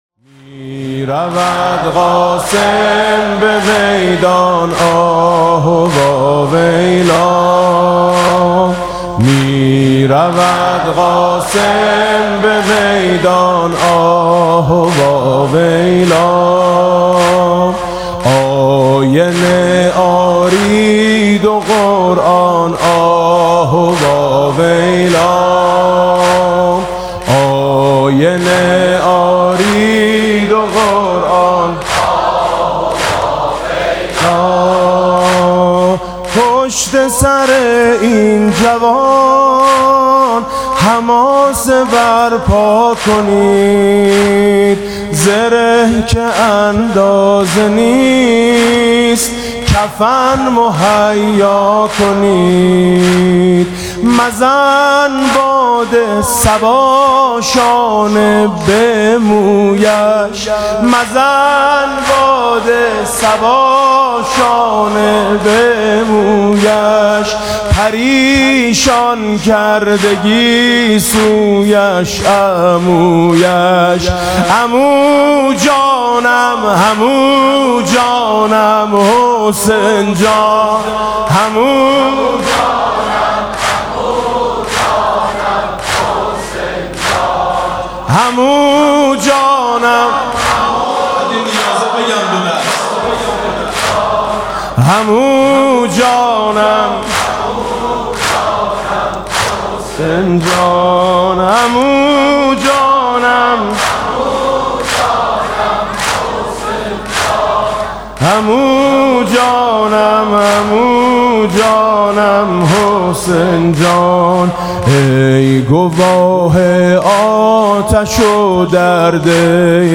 مراسم عزاداری شب ششم محرم ۱۴۰۳ با صدای میثم مطیعی
مبادا غزه را تنها گذاری (نوحه سنتی)‌